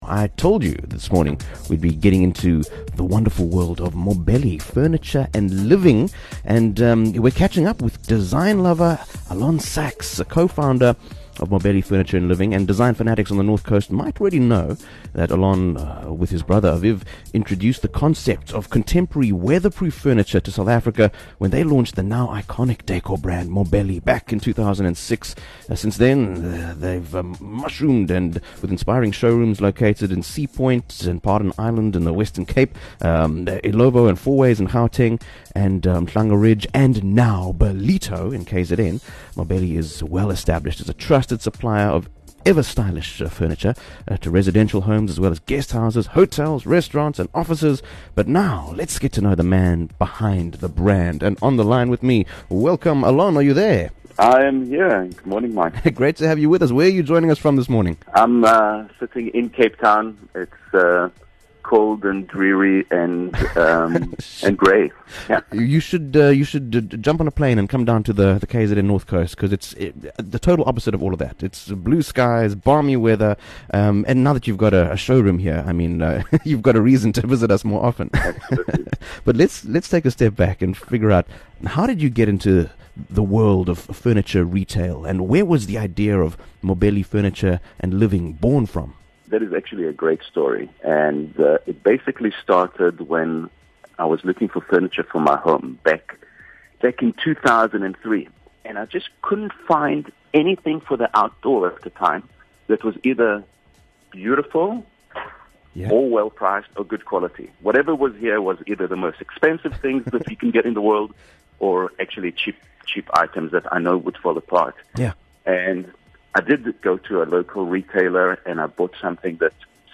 Mobelli Furniture & Living: An Interview